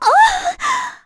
Erze-Vox_Damage_kr_02.wav